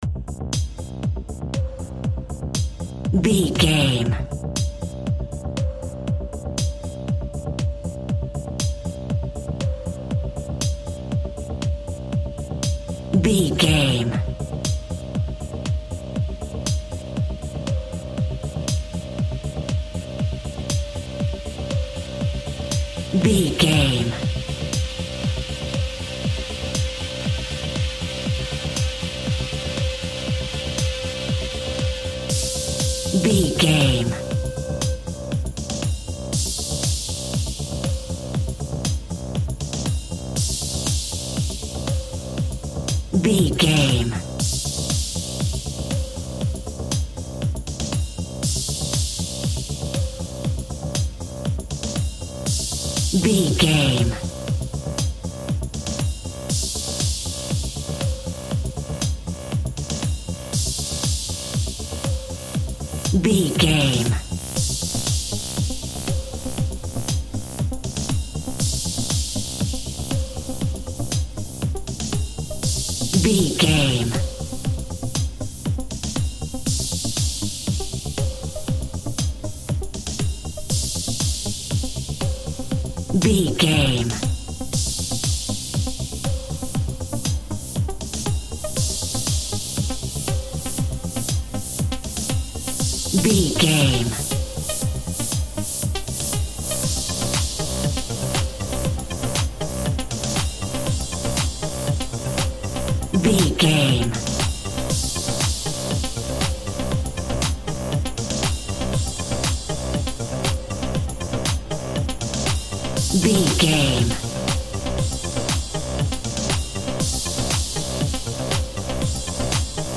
Modern Pop Top 40 Electronic Dance Music Full.
Fast paced
Aeolian/Minor
Fast
dark
futuristic
groovy
aggressive
repetitive
synthesiser
drum machine
house
techno
instrumentals
synth leads
synth bass
upbeat